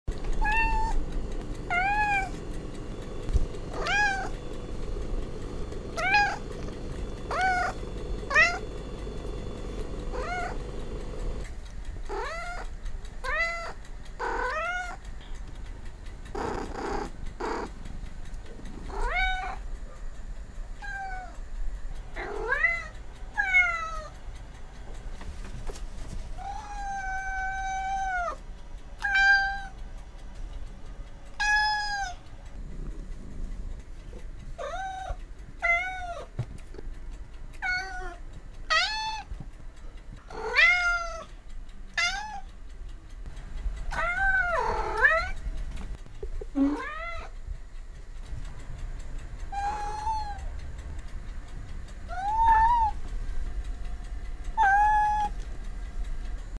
3D sounds - Cat Meow 41841
• Category: 3d sound